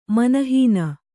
♪ mana hīna